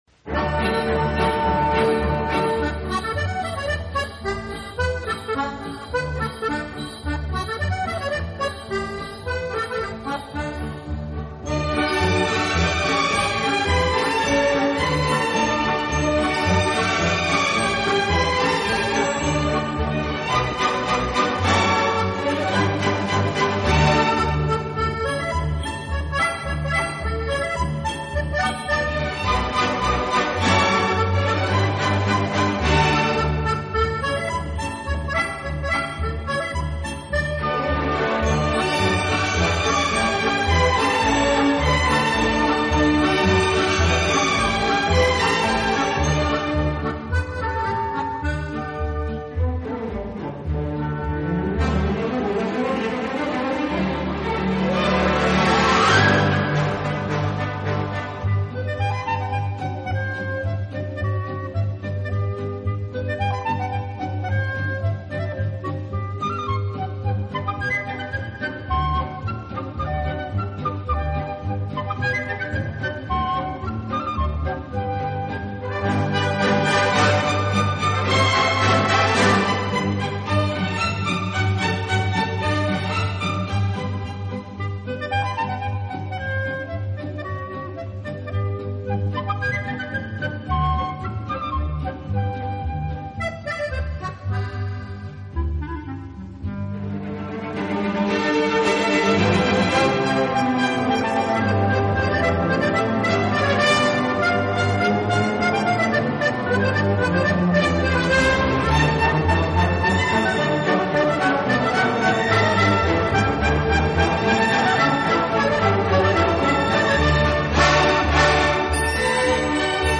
【轻音乐】
Genre: Instrumental
舒展，旋律优美、动听，音响华丽丰满。